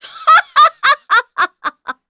Seria-Vox_Happy3_kr.wav